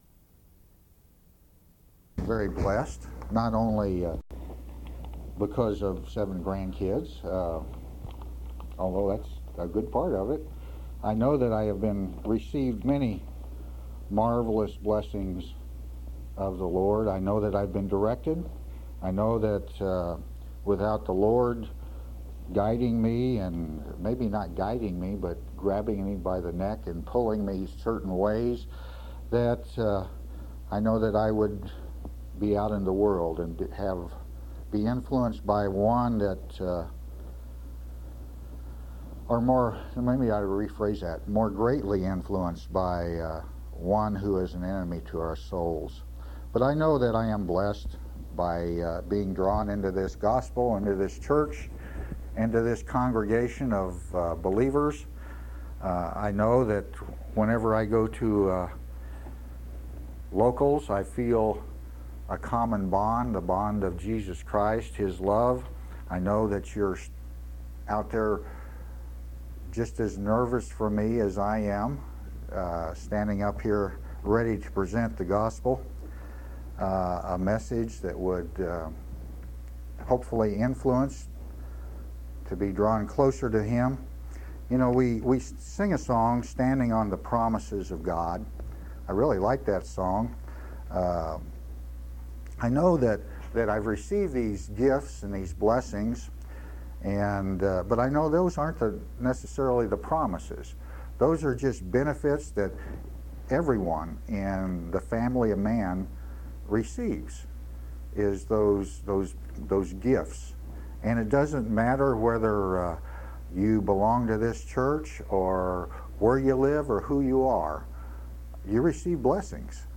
7/20/2008 Location: Collins Local Event